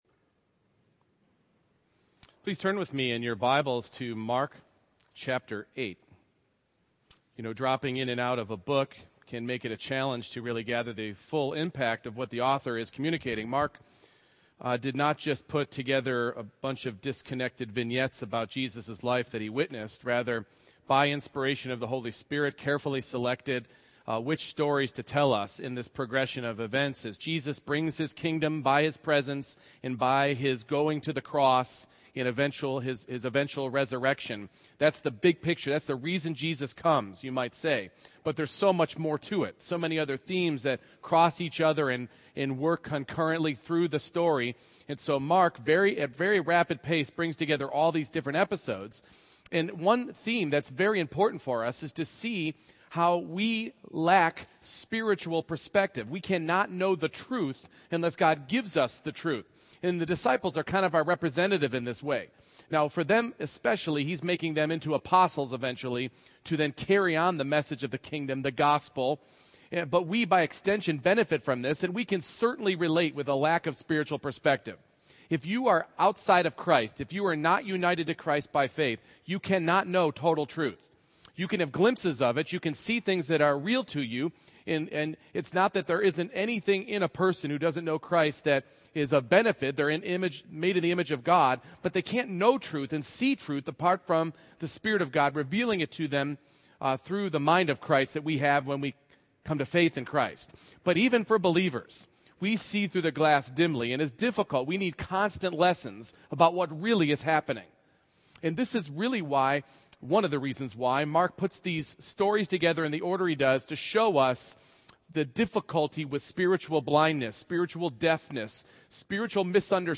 Mark 8:17-26 Service Type: Morning Worship Jesus brings true sight in His time and in His way.